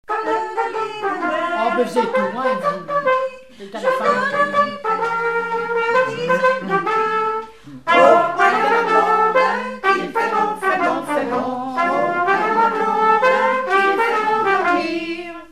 Enquête Arexcpo en Vendée-Pays Sud-Vendée
Pièce musicale inédite